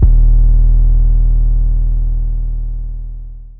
Tripp3 808 3 (Dro Long).wav